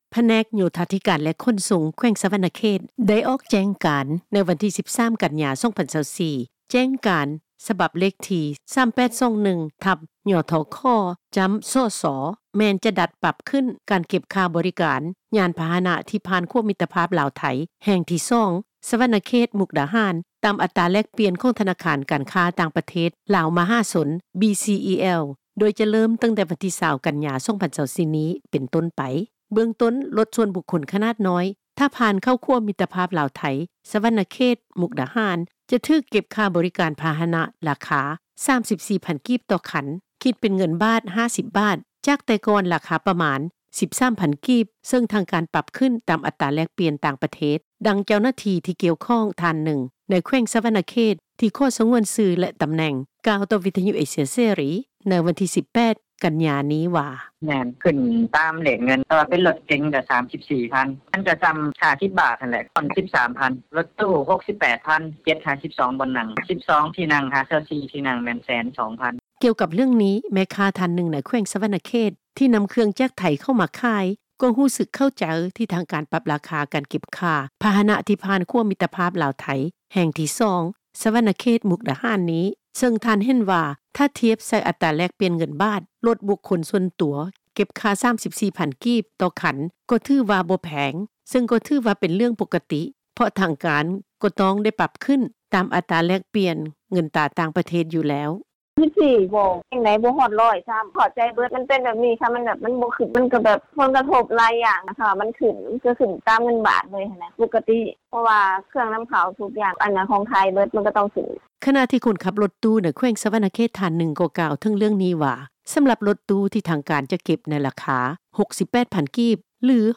ດັ່ງ ເຈົ້າໜ້າທີ່ ທີ່ກ່ຽວຂ້ອງ ທ່ານນຶ່ງ ໃນເຂດ ສະຫວັນນະເຂດ ທີ່ຂໍສະຫງວນຊື່ ແລະ ຕໍາແໜ່ງ ກ່າວຕໍ່ວິທຍຸ ເອເຊັຽເສຣີ ໃນມື້ວັນທີ 18 ກັນຍາ ນີ້ວ່າ: